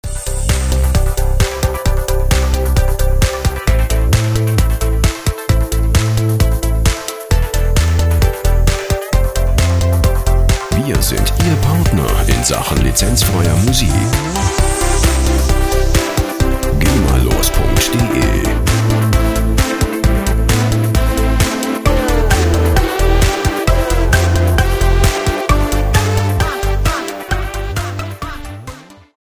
freie Musikbetts für Ihre Radiosendung
Musikstil: Christmas Pop
Tempo: 132 bpm